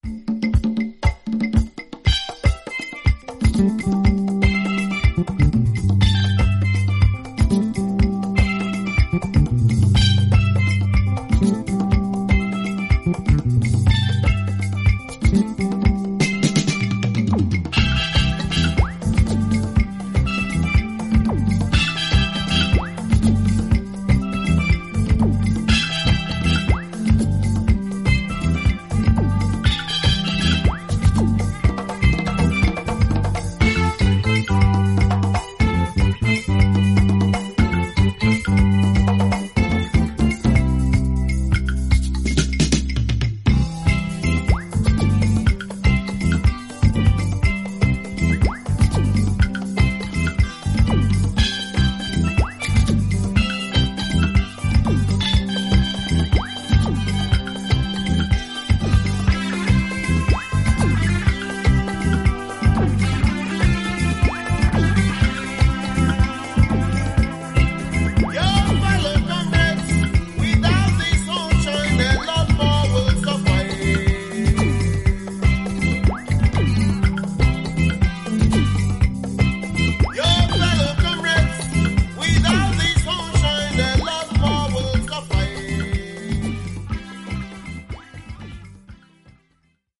UKアフリカン/カリビアン混成バンド